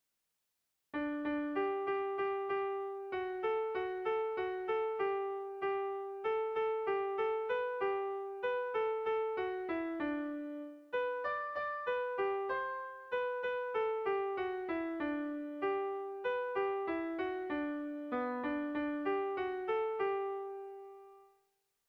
Erlijiozkoa
Zortziko txikia (hg) / Lau puntuko txikia (ip)
ABDE